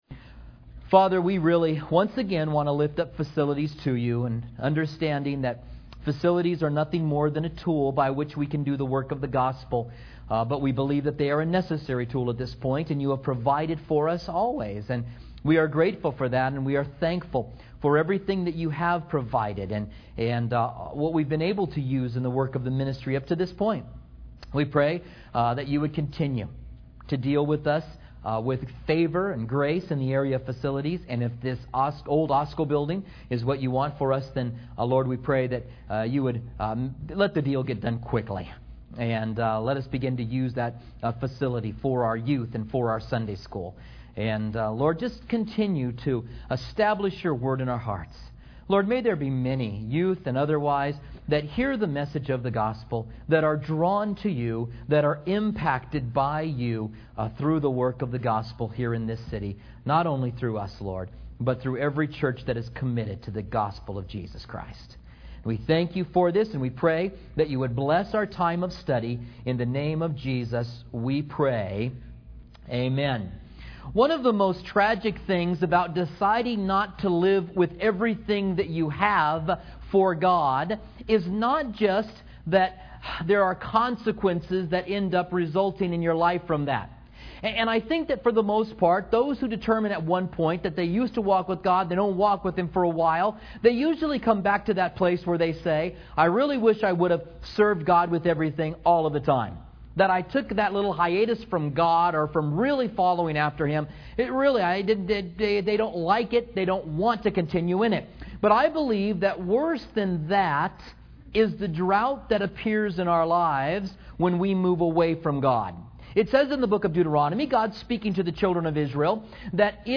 Commentary on 1 Kings